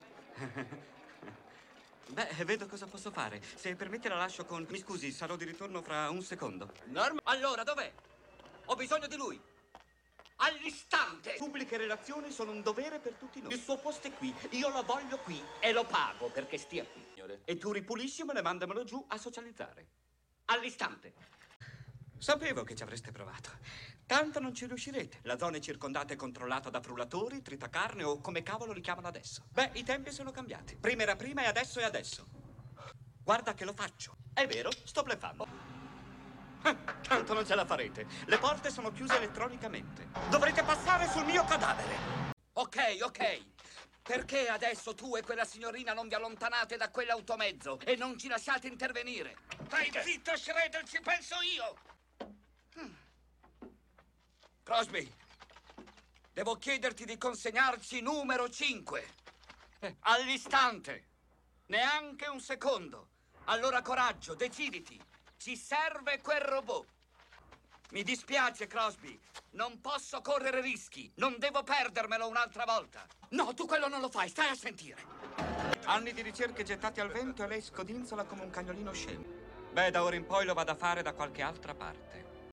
nel film "Corto circuito", in cui doppia Austin Pendleton.